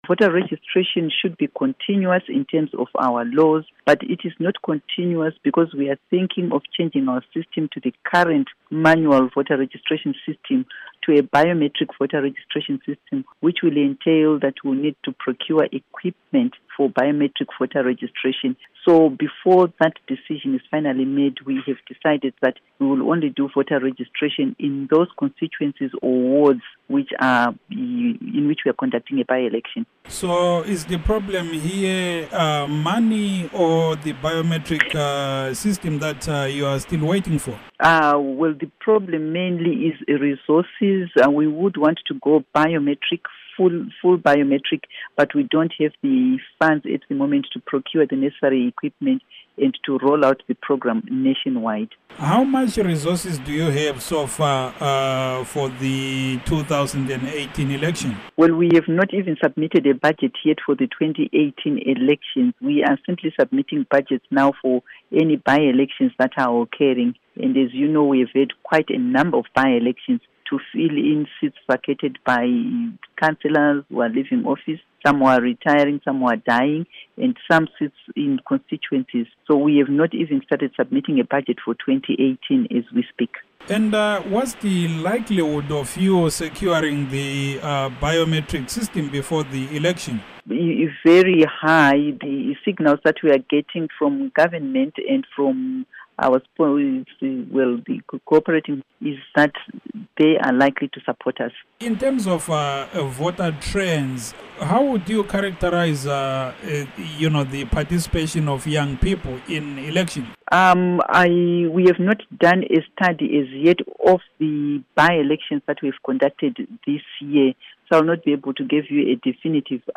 Interview With Rita Makarau